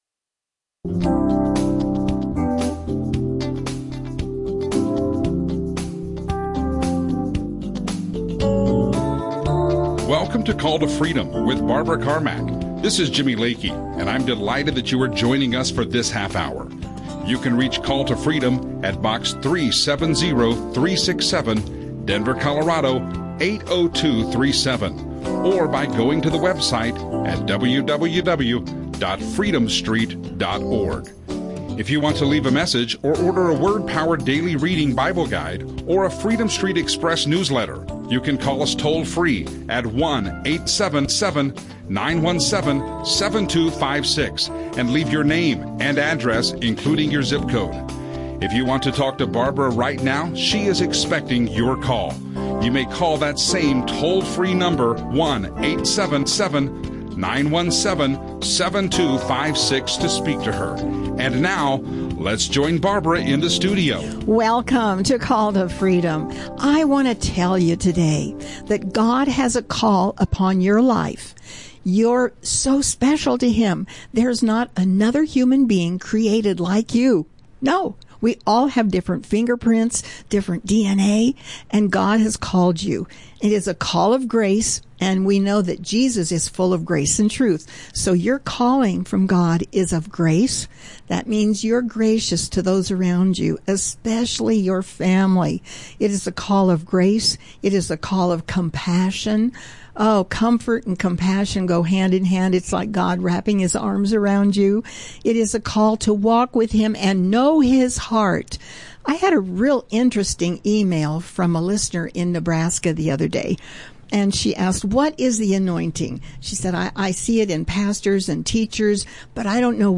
Biblical teaching